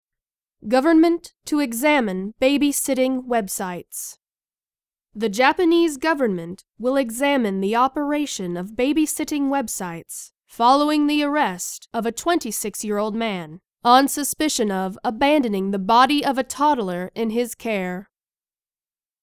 （区切りなしのナレーションです。）